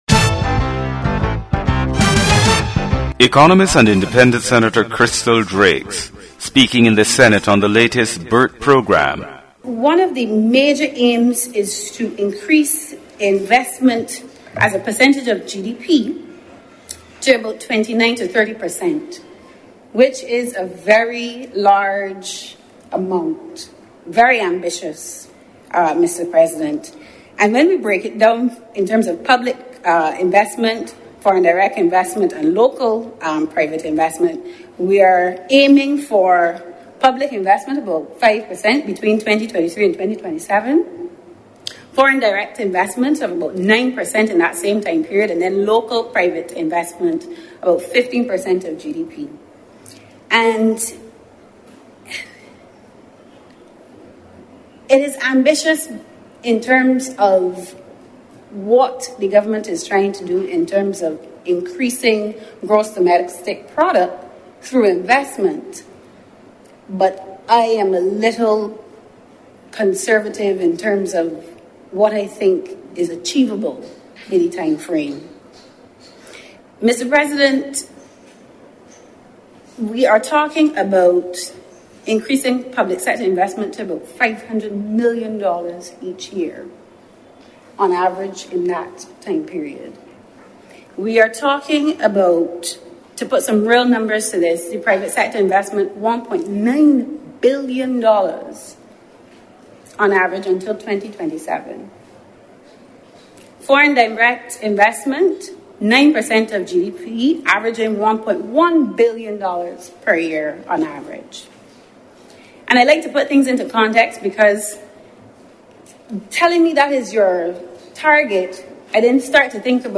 Economist and independent Senator Crystal Drakes speaking in the Senate on the latest BERT program. She states that one of the major aims is to increase investment as a percentage of GDP to about 29% to 30%, which is a very large amount.